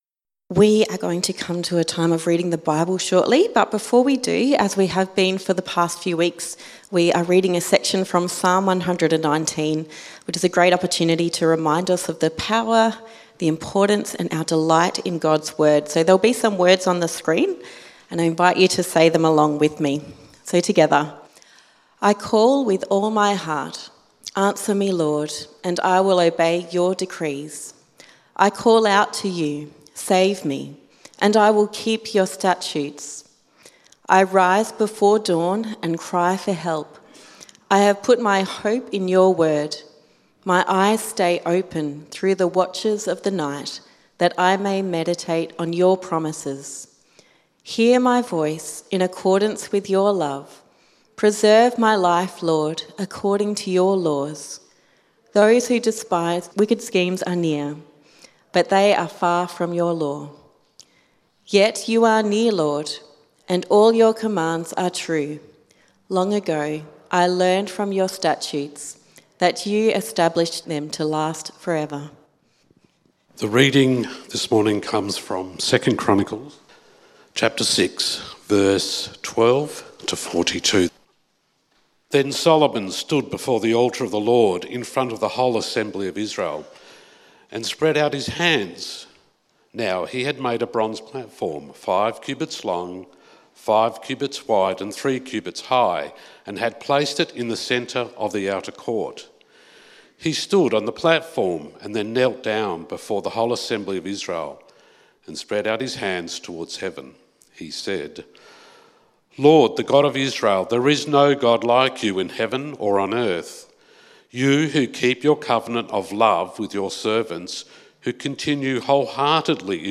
Great Prayers in the Bible Sermon outline